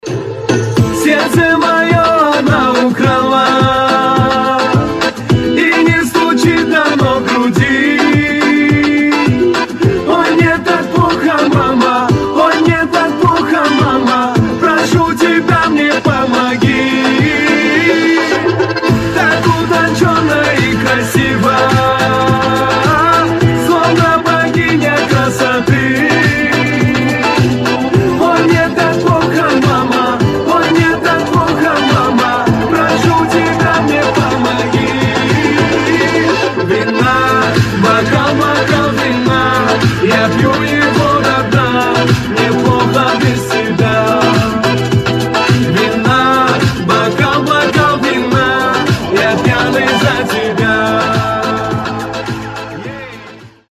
мужской вокал
восточные мотивы
кавказские